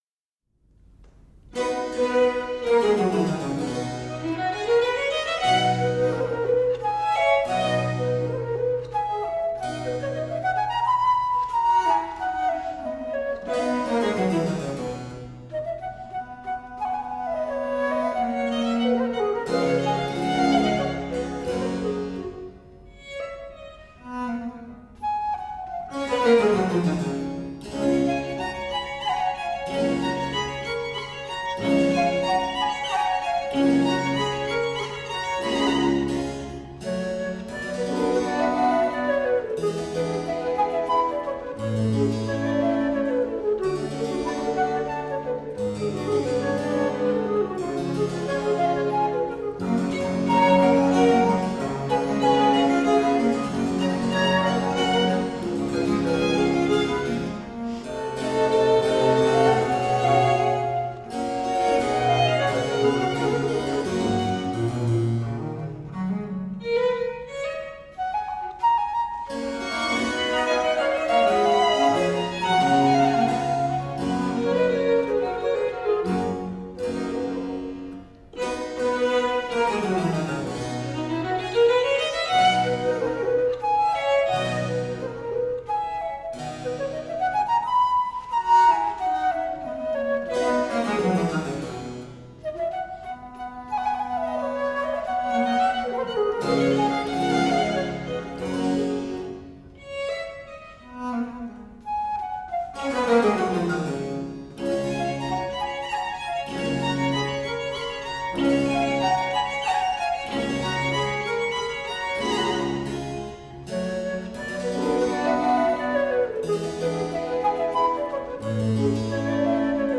Rare and extraordinary music of the baroque.
lightly elegant dance music
violinist
flutist
harpsichord
violoncello